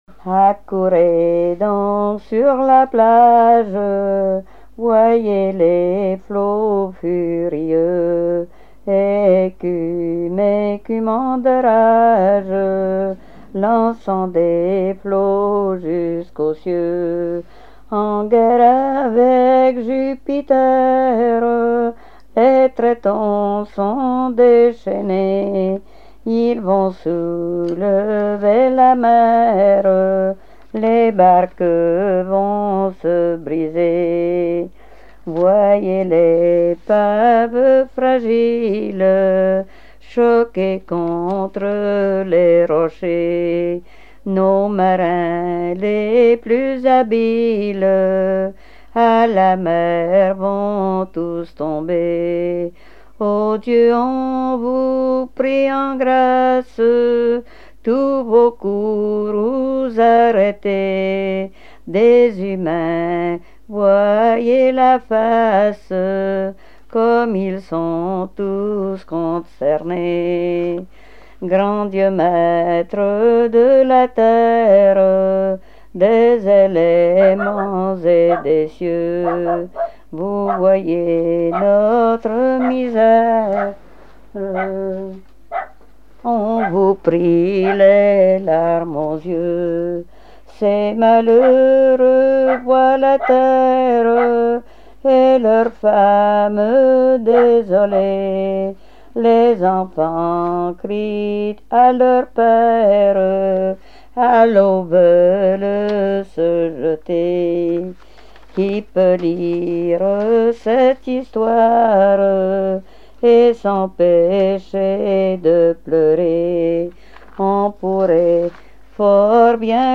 Répertoire de chansons
Pièce musicale inédite